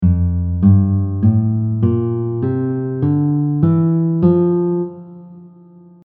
The F Major scale has the notes F, G, A, Bb, C, D, and E. These notes come from the Major scale formula which has specific steps between each note (whole, whole, half, then whole, whole, whole half step).
F Major scale formula
F-Major-scale-guitar.mp3